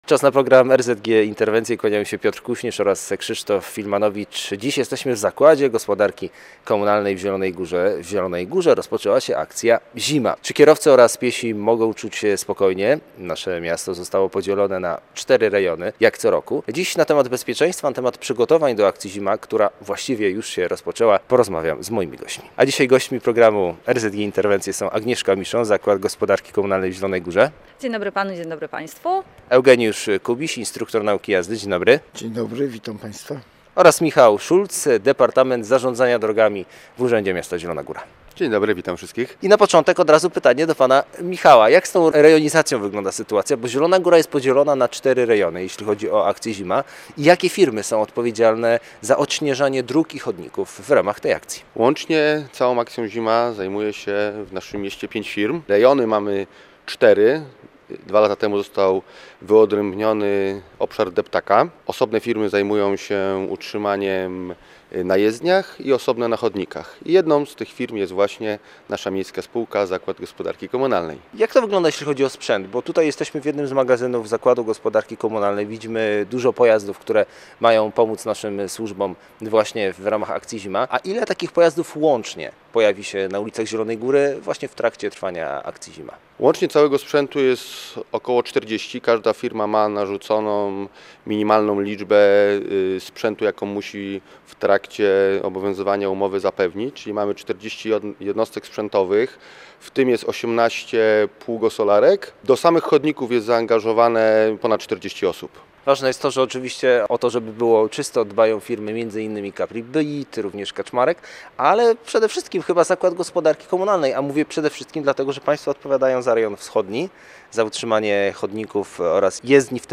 Do dyspozycji Zakładu Gospodarki Komunalnej oraz kilku zewnętrznych firm jest kilkadziesiąt pojazdów, wyposażonych w GPS-y. Dziś na temat akcji „ZIMA” oraz o tym jak bezpiecznie jeździć po śniegu rozmawiali zaproszeni goście: